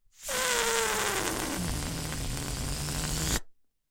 气球 " 气球放气短3
描述：录制为通过操纵气球创建的声音集合的一部分。
标签： 放气 折边 气球 放屁
声道立体声